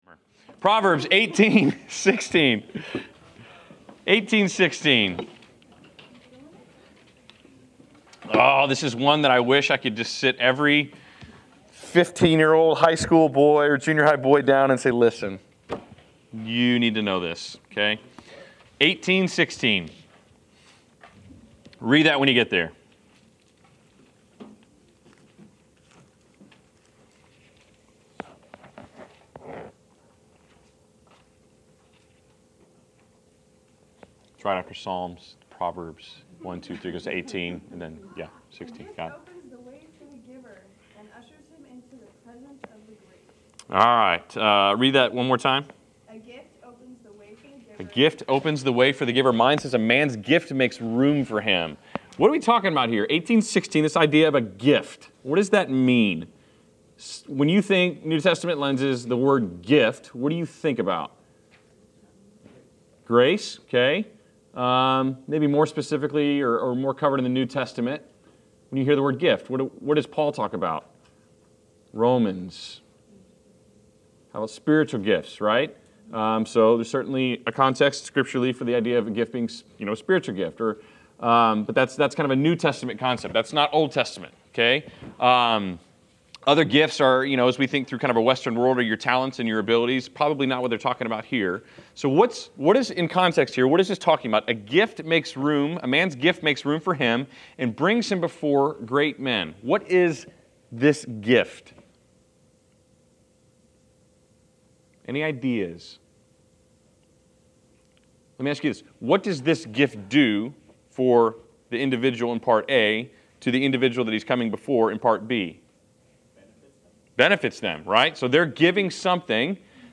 Class Session Audio